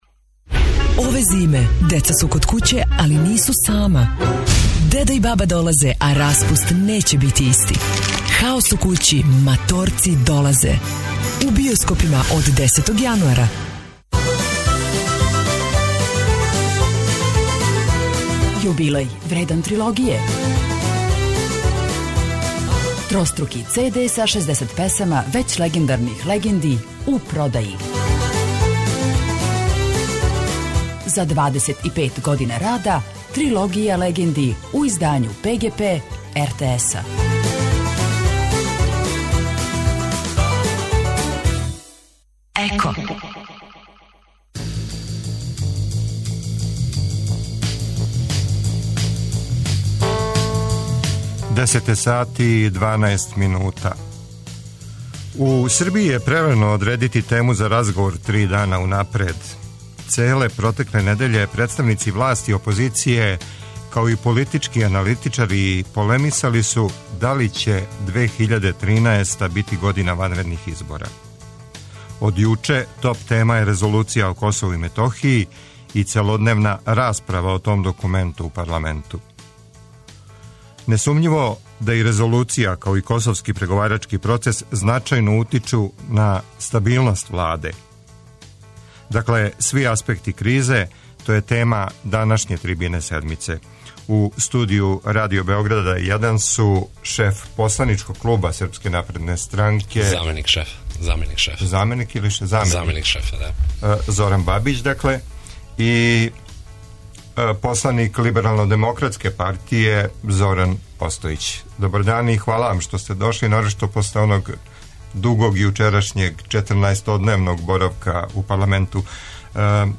За Седмицу говоре шефови посланичких група владајуће и најјаче опозиционе партије, Зоран Банић и Борко Стефановић.